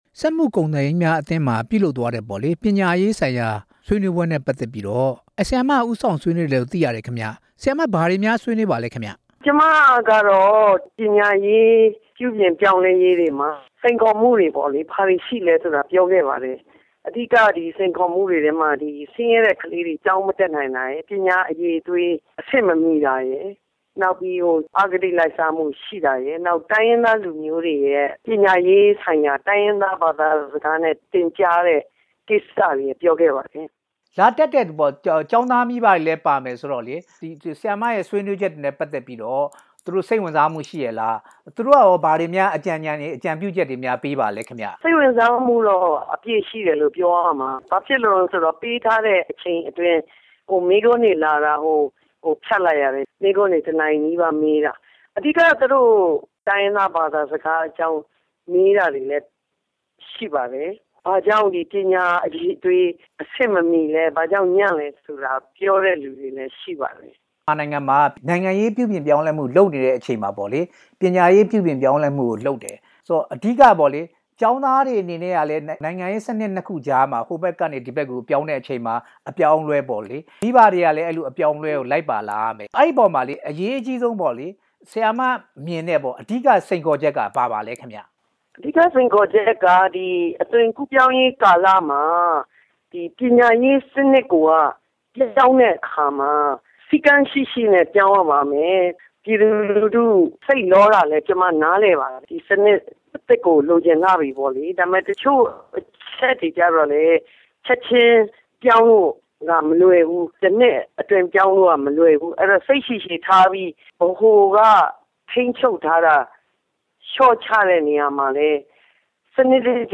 ဒေါက်တာဒေါ်ရင်ရင်နွယ်နဲ့ မေးမြန်းချက်